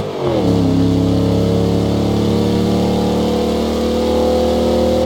Index of /server/sound/vehicles/lwcars/chev_suburban